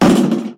impact-4.ogg